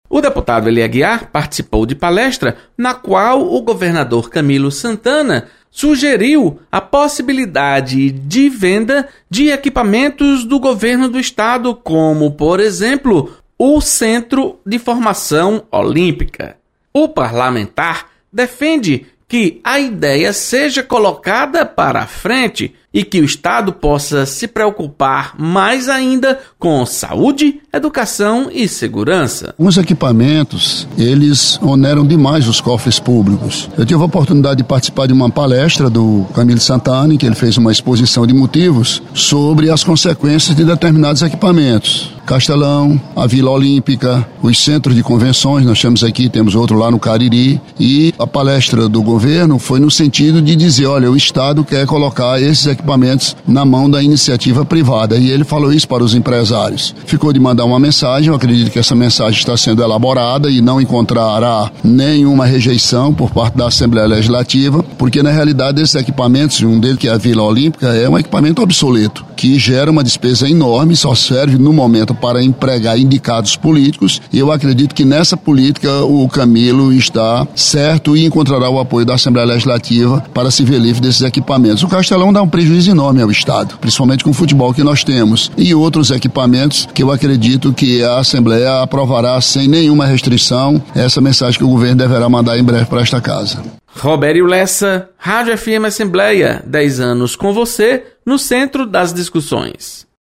Deputado Ely Aguiar defende privatização de equipamentos do Governo do estado. Repórter